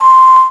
55bw-flt17-c6.aif